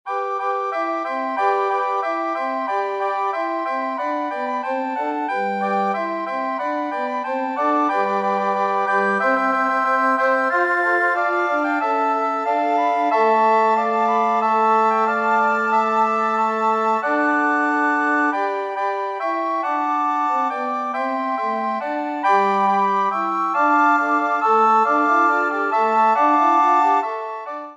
S A T B + S A T B